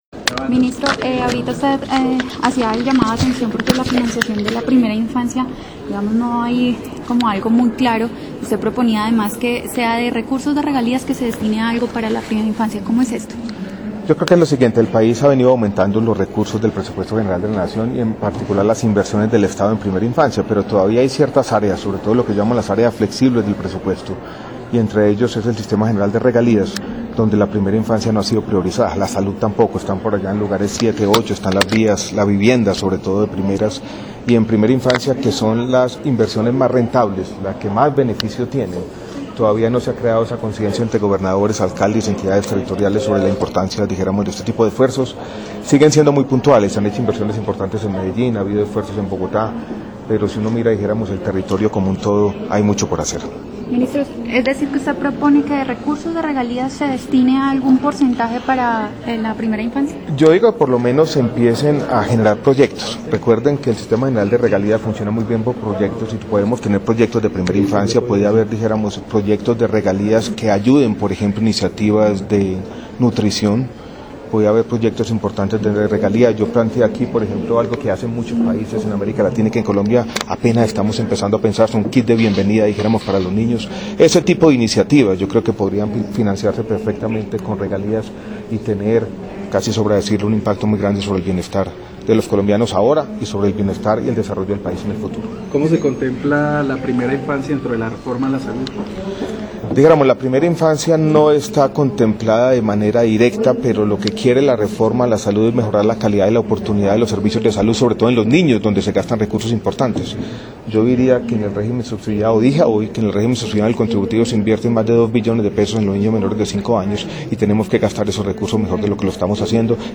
Audio, MinSalud habla sobre la política de atención integral a la primera infancia
Bogotá, 20 de marzo de 2014.- En el marco del foro ‘Reflexiones sobre la política de atención integral a la primera infancia’ organizado por Presidencia de la República y el Banco Interamericano de Desarrollo (BID), el Ministro de Salud y Protección Social, Alejandro Gaviria Uribe, aseguró que con la nueva estructura presupuestal del país los recursos de regalías pueden ser utilizados en un porcentaje importante para inversión en programas dirigidos a esta población.